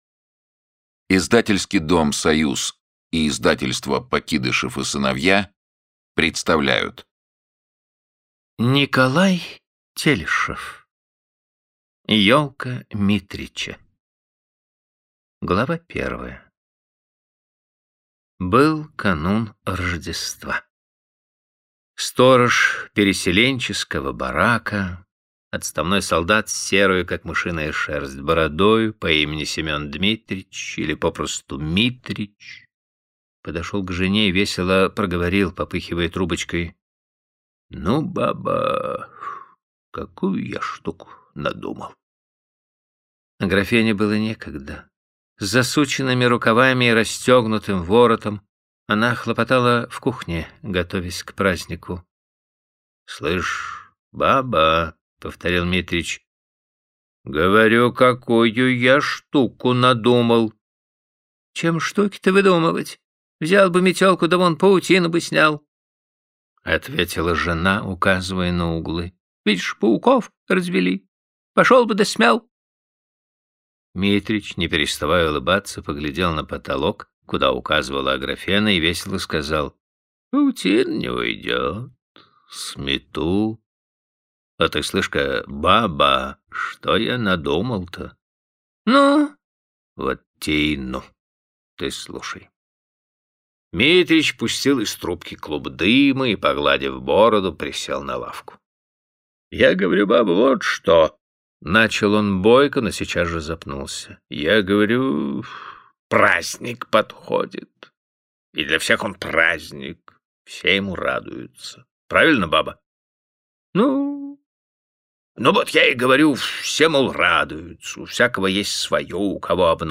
Аудиокнига Ёлка Митрича | Библиотека аудиокниг